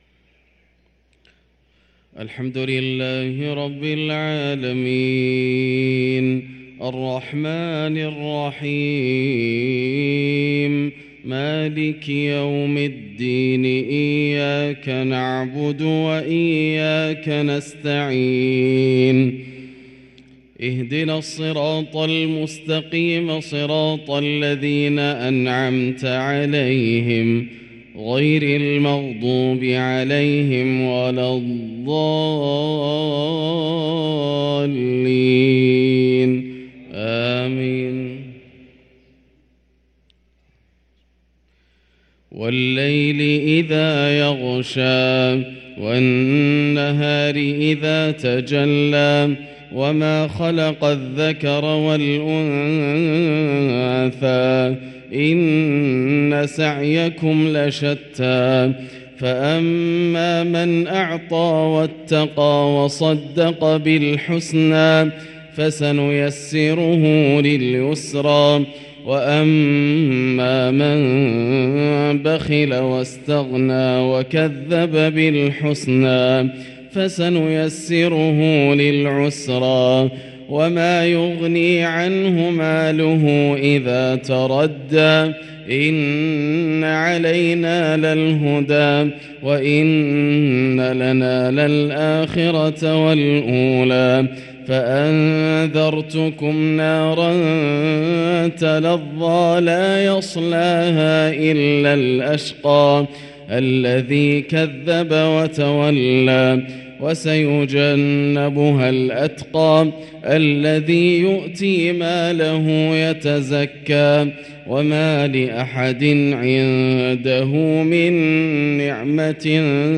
صلاة العشاء للقارئ ياسر الدوسري 3 رجب 1444 هـ
تِلَاوَات الْحَرَمَيْن .